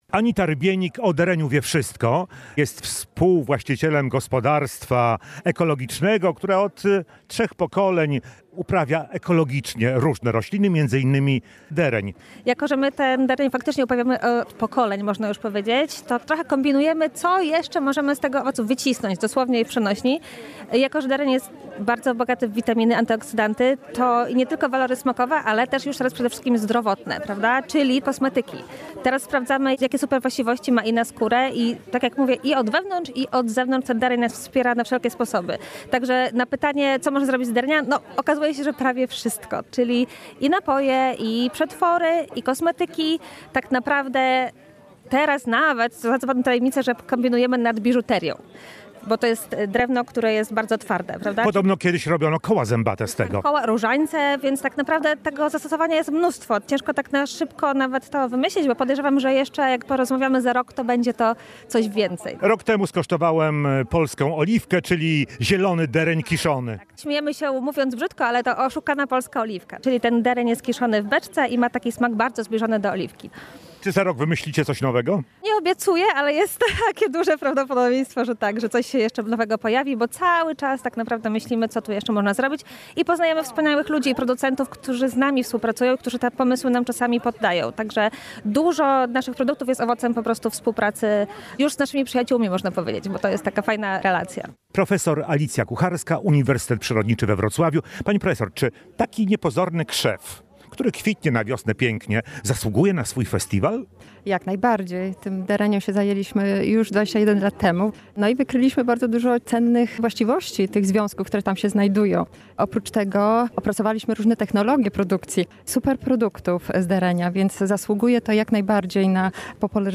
Kilka tysięcy osób odwiedziło w niedzielę (14.09.) Arboretum w podprzemyskich Bolestraszycach, gdzie odbył się XIV Międzynarodowy Festiwal Derenia.